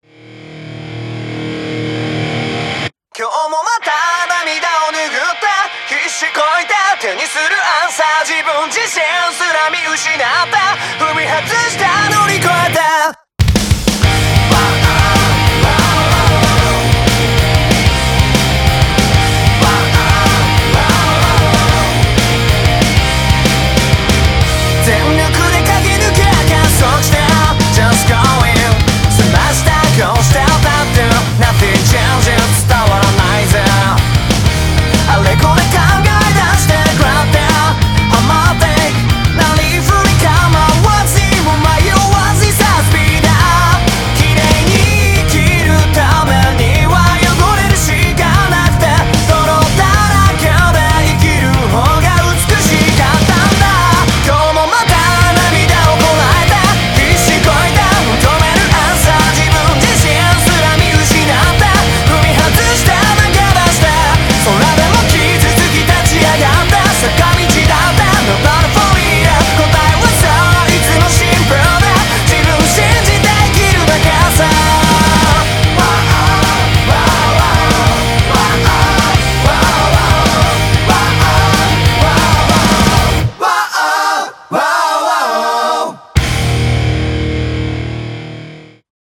BPM188
Audio QualityPerfect (High Quality)
first opening theme